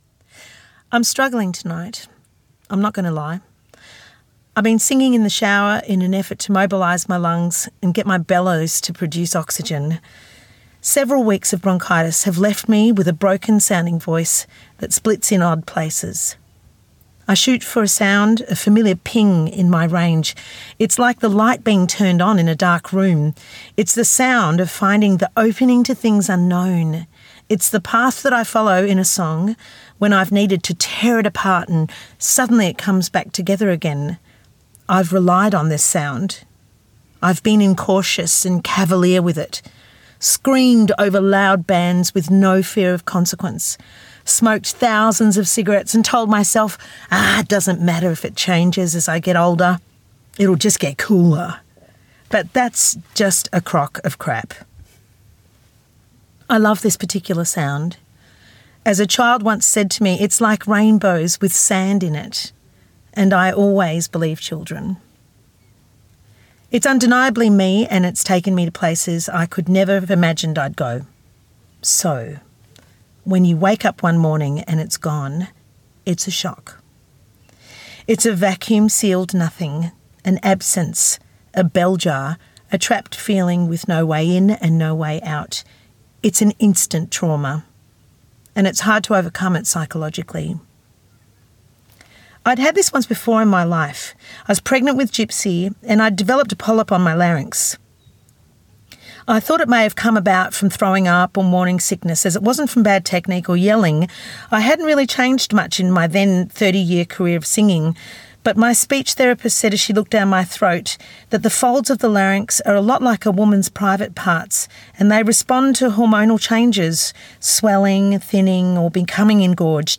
Recorded at Byron Writers Festival 2024
Kate-ceberano-read.mp3